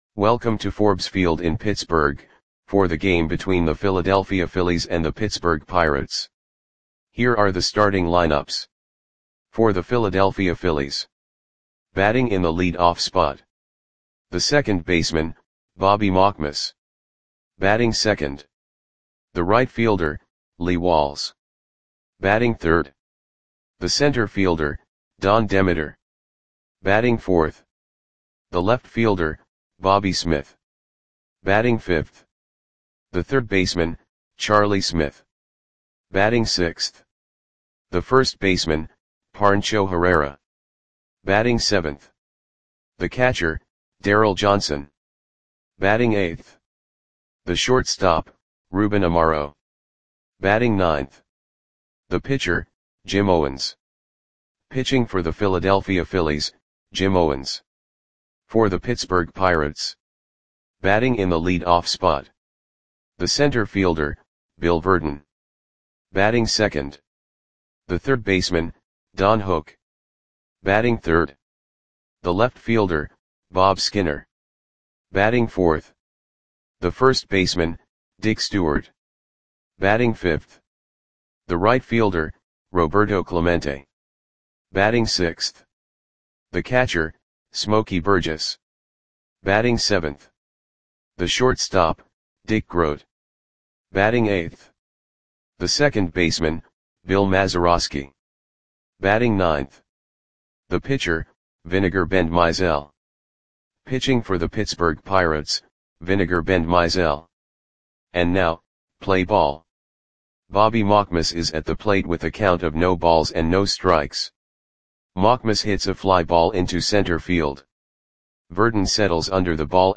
Audio Play-by-Play for Pittsburgh Pirates on August 12, 1961
Click the button below to listen to the audio play-by-play.